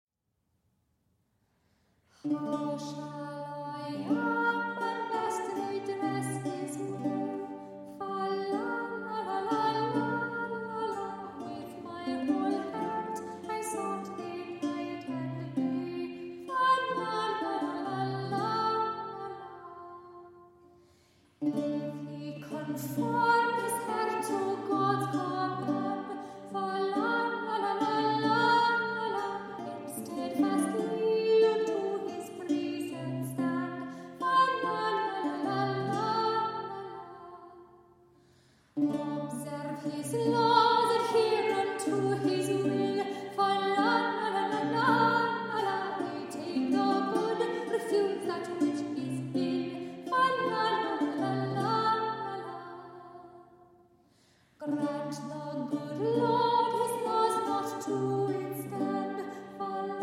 Soprano
Renaissance Lute